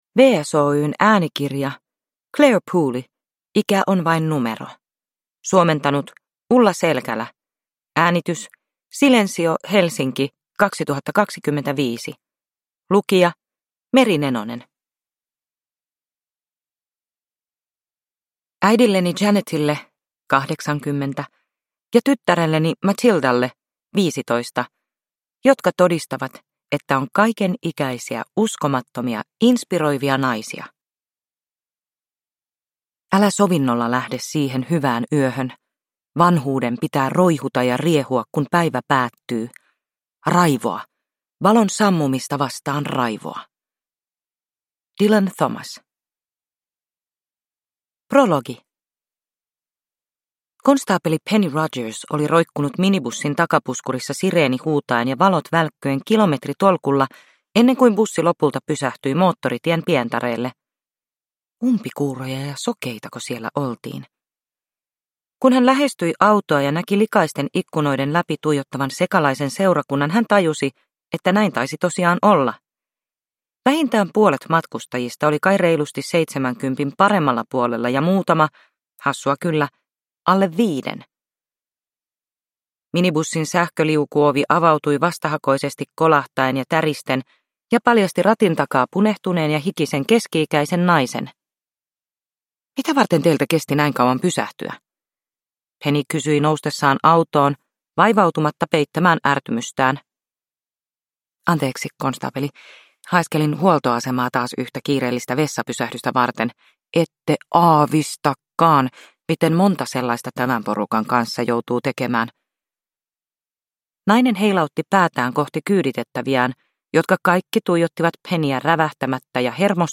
Ikä on vain numero – Ljudbok